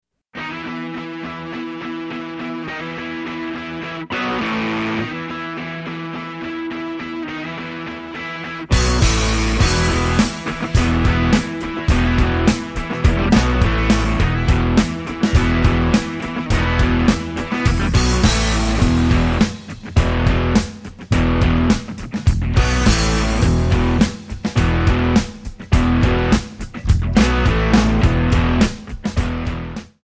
Backing track files: Rock (2136)
Buy With Backing Vocals.